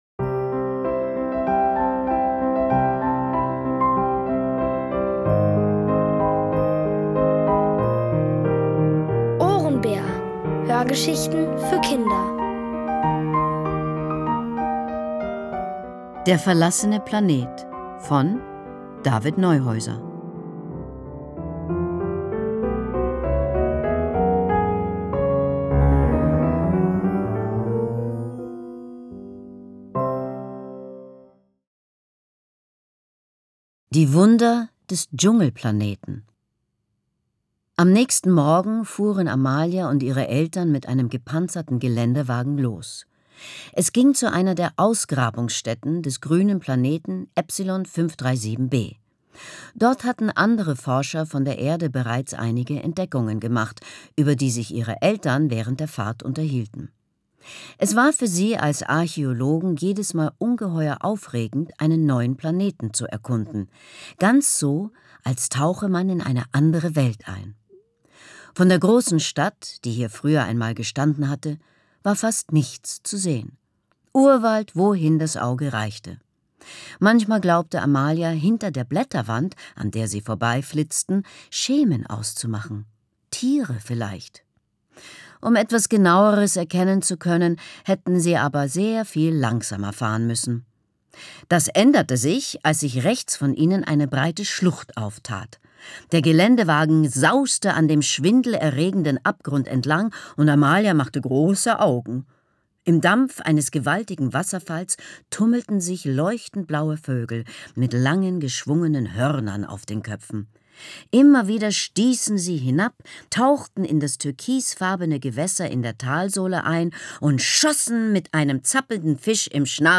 Von Autoren extra für die Reihe geschrieben und von bekannten Schauspielern gelesen.
Es liest: Leslie Malton.